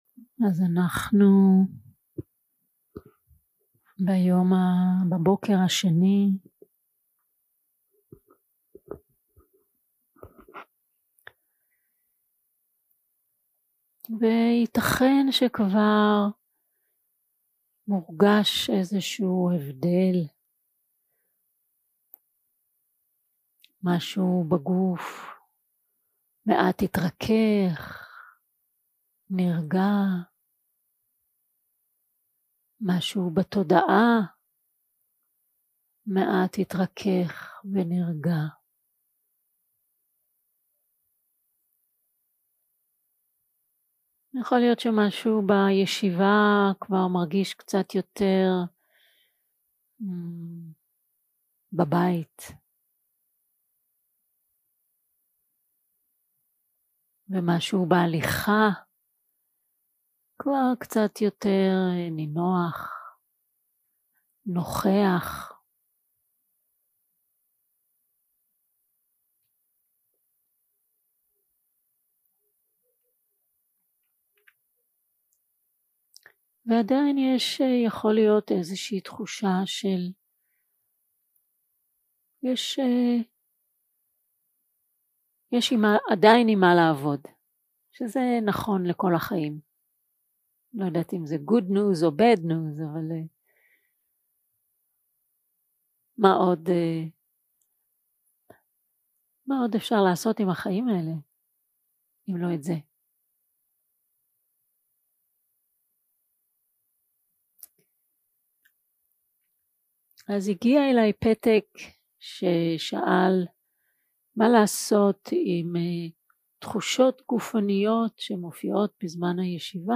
יום 3 – הקלטה 5 – בוקר – הנחיות למדיטציה – התמודדות עם כאב 0:00 0:00 סוג ההקלטה: Dharma type: Guided meditation שפת ההקלטה: Dharma talk language: Hebrew